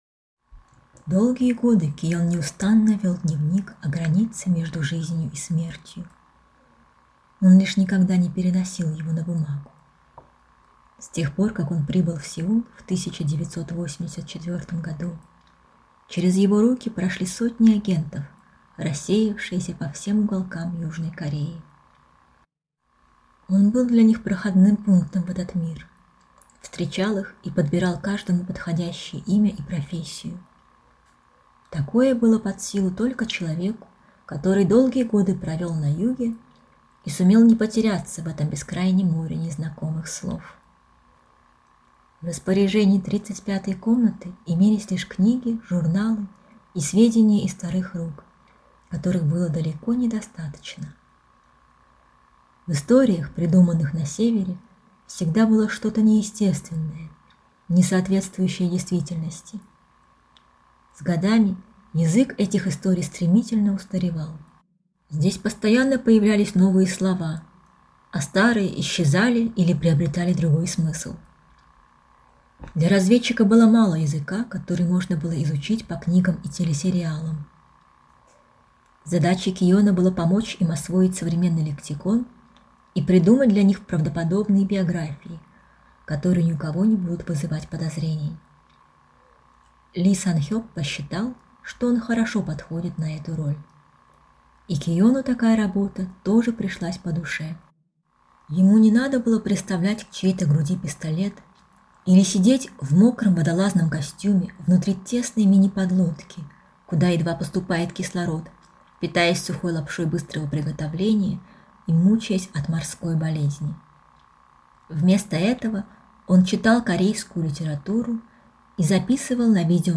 Каждый переводчик читает свой текст; вы услышите 11 голосов и 11 неповторимых творческих интонаций.